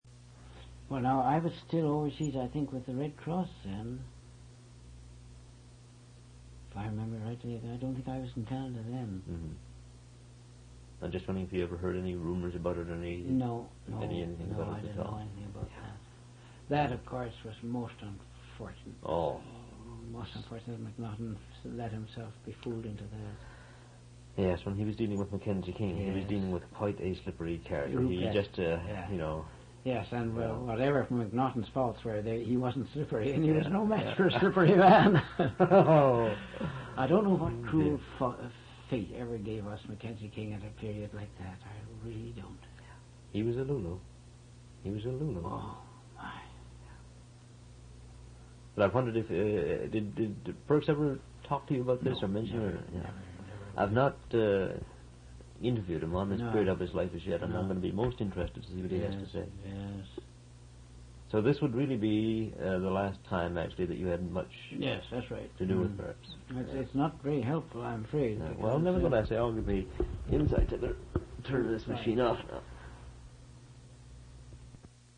Interviewee: Price, Charles Basil, b. 1889
An interview/narrative of Charles Basil Price's recollections of Major-General Pearkes.
One original sound tape reel (ca. 52 min.) : 1 7/8 ips, 2 track, mono.